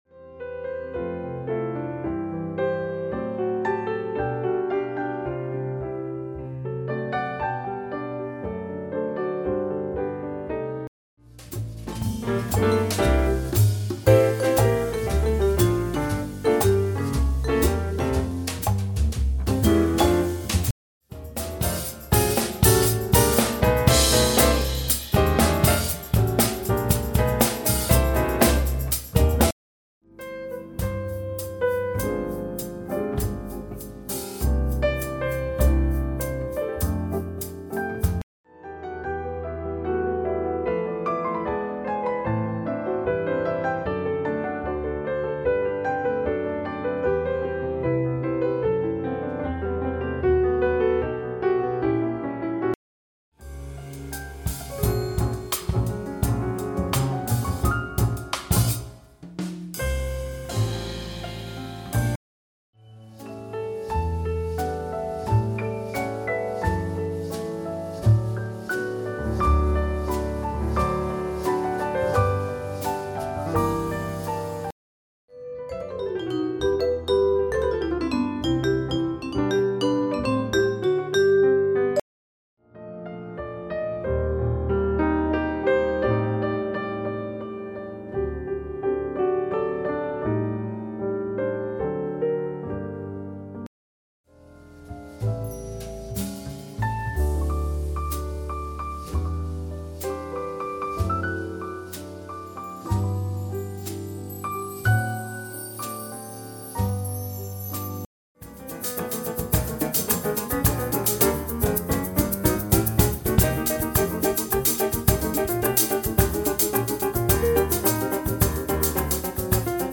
Van klassiek tot jazz.
drums
percussie.